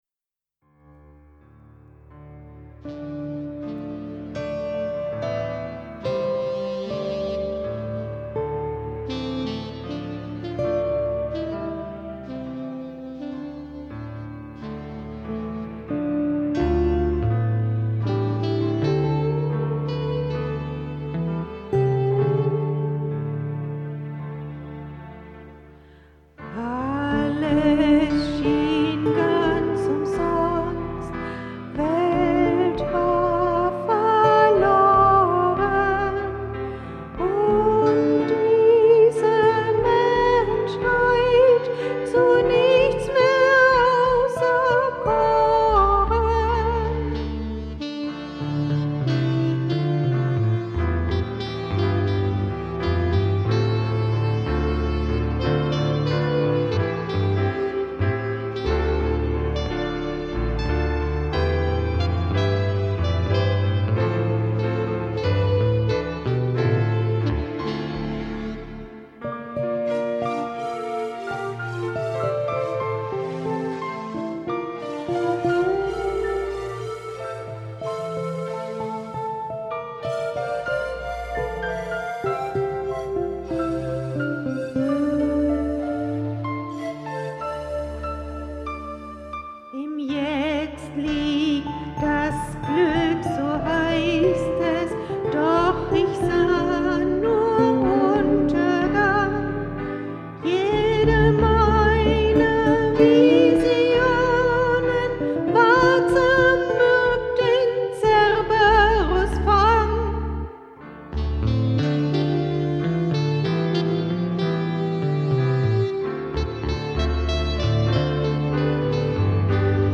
wunderbare Gesänge und ergreifende Instrumentalstücke